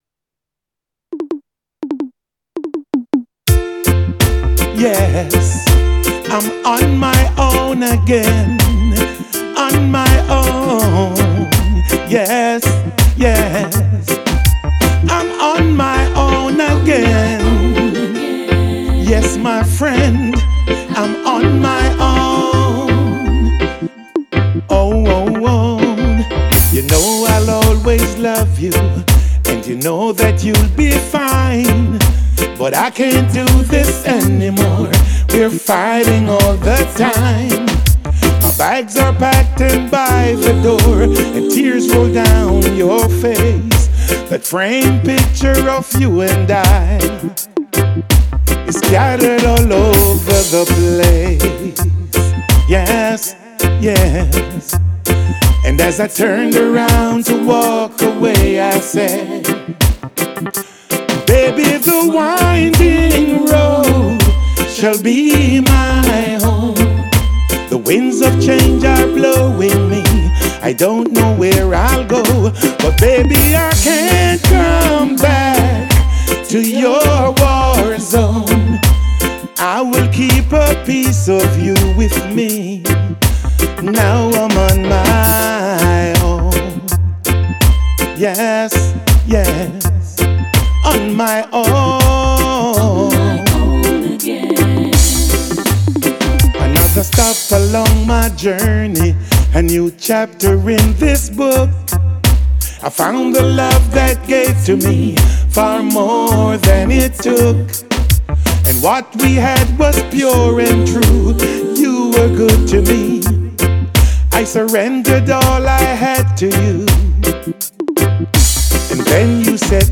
reggae riddim